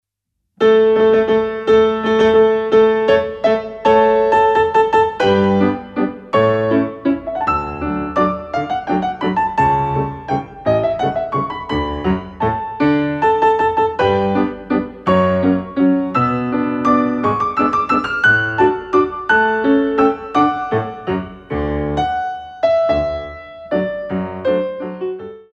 Double tours hommes